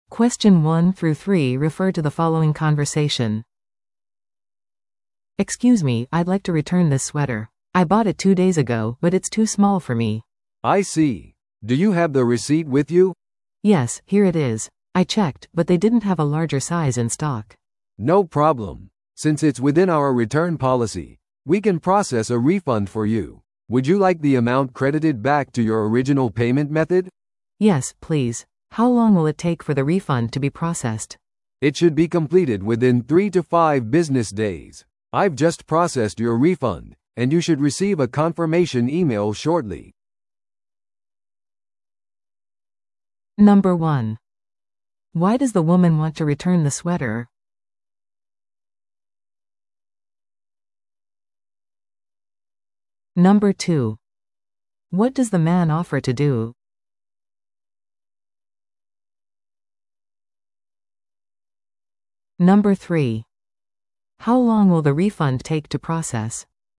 No.1. Why does the woman want to return the sweater?
No.2. What does the man offer to do?